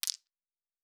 Fantasy Interface Sounds
Objects Small 14.wav